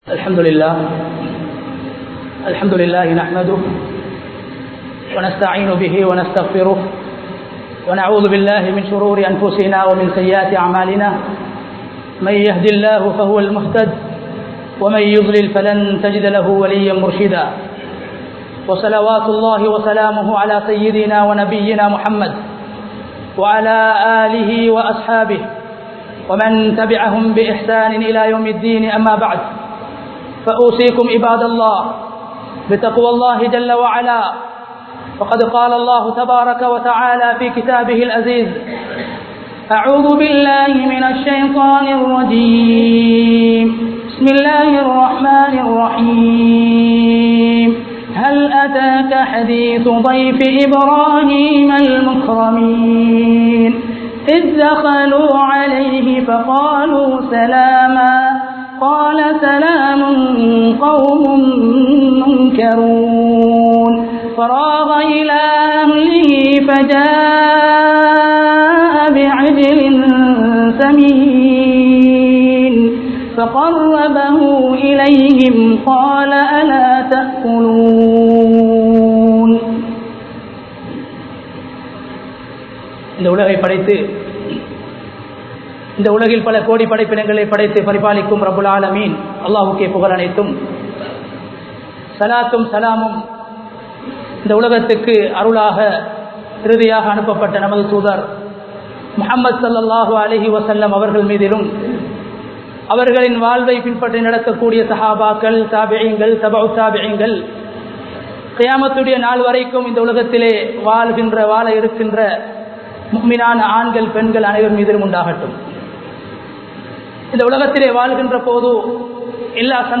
Ibrahim Nabien MunMaathirihal (இப்றாஹிம் நபியின் முன்மாதிரிகள்) | Audio Bayans | All Ceylon Muslim Youth Community | Addalaichenai
Colombo 09, Minnan Jumua Masjith 2019-07-05 Tamil Download